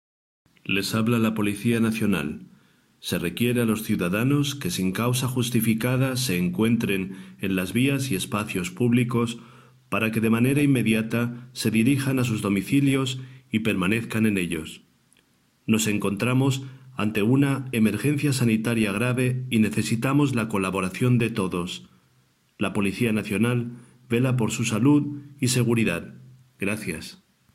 La Policía Nacional está emitiendo, desde el día de hoy, un comunicado en formato de audio que pretende concienciar a las personas que se encuentran en la calle de la importancia que tiene permanecer en casa: "Se requiere a los ciudadanos que, sin causa justificada, se encuentra en las vías y espacios públicos, se dirijan de manera inmediata a sus domicilios".
Escucha el audio completo de la Policía Nacional en castellano